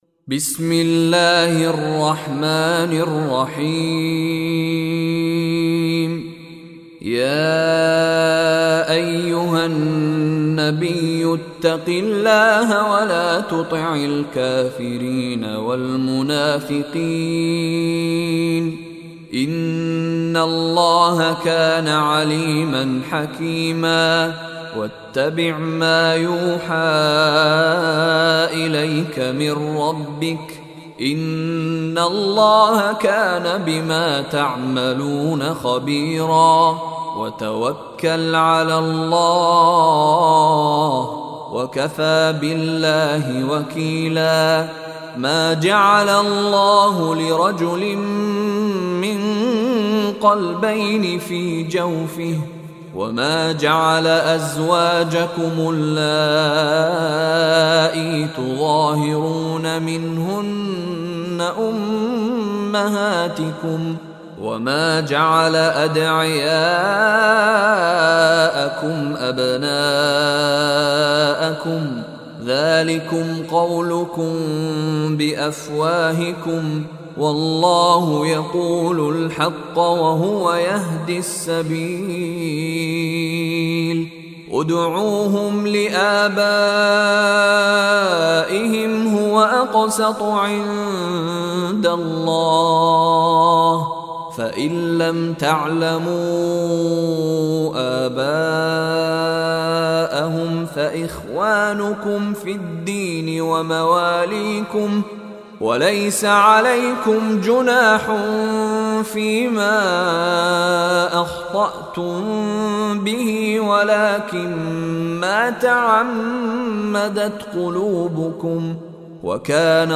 Surah Al Ahzab Recitation by Sheikh Mishary Rashid
Listen online and download beautiful Quran tilawat / recitation of Surah al Ahzab in the voice of Sheikh Mishary Rashid Alafasy.